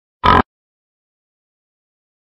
Copyright free Sound-Effect Pig oink sound effect royalty free royalty free
Category: Sound FX   Right: Personal